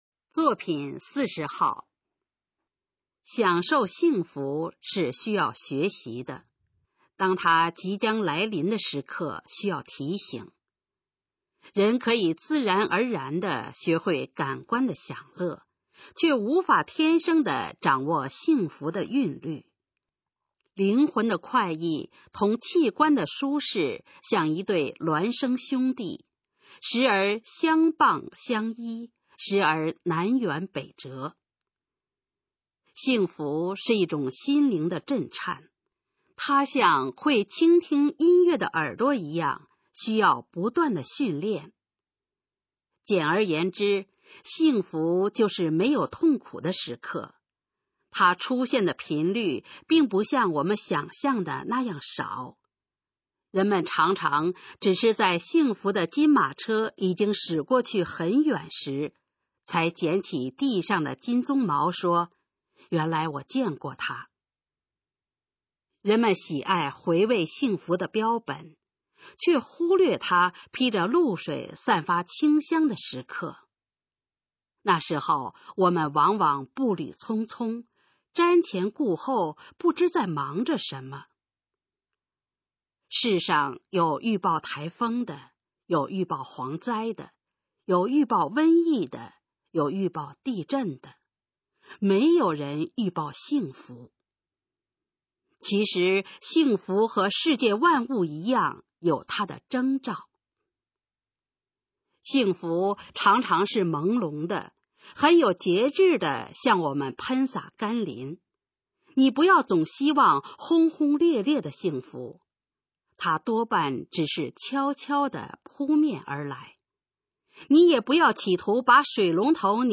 首页 视听 学说普通话 作品朗读（新大纲）
《提醒幸福》示范朗读_水平测试（等级考试）用60篇朗读作品范读